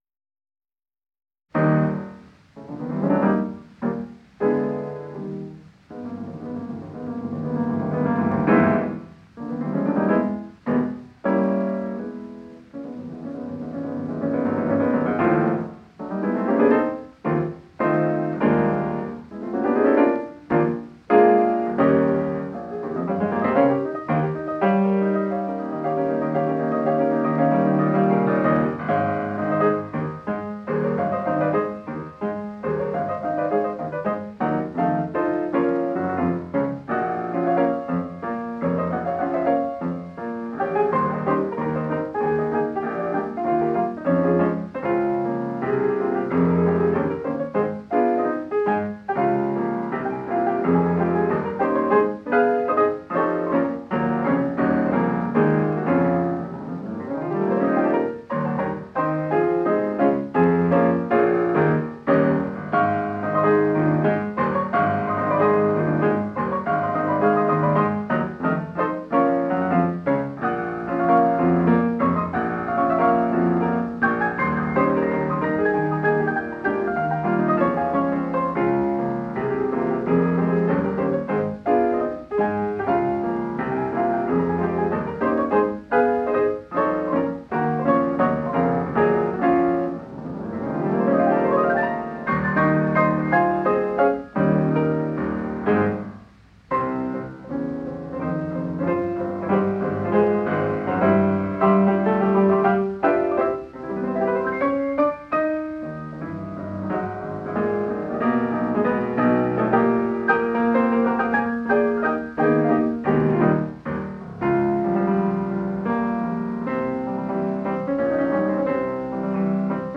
（　英雄ポロネーズ　ピアノ演奏　）